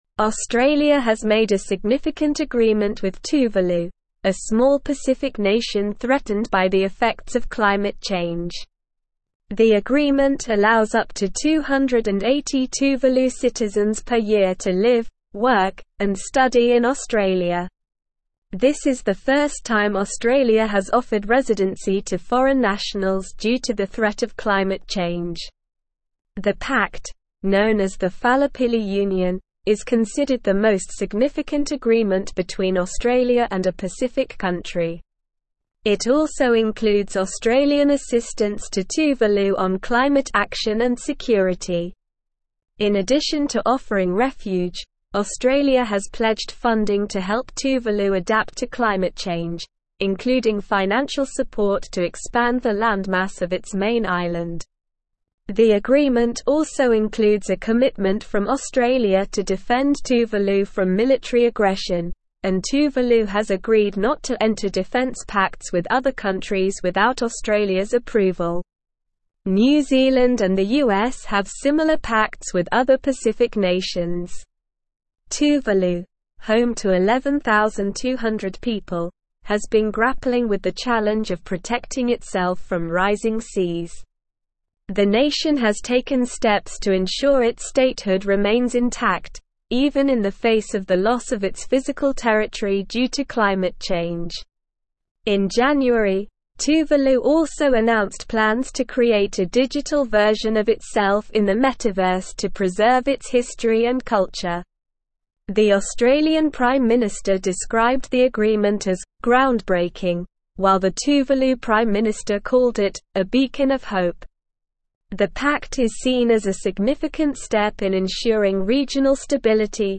Slow
English-Newsroom-Advanced-SLOW-Reading-Australias-Historic-Pact-Refuge-for-Tuvaluans-from-Climate-Change.mp3